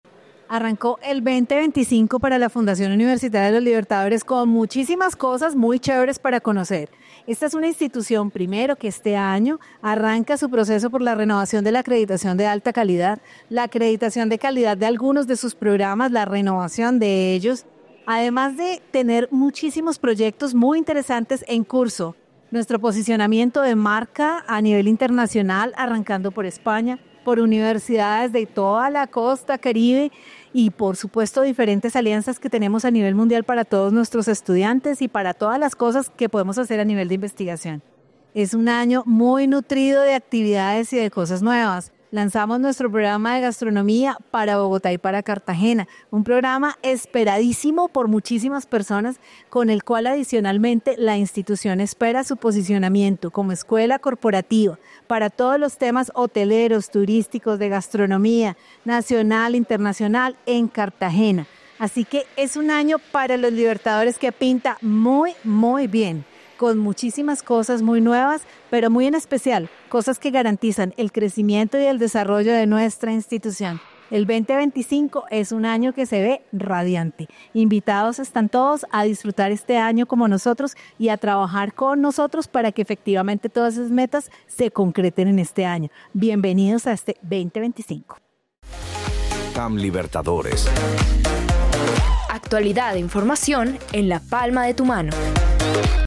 En la siguiente entrevista profundiza más en el tema.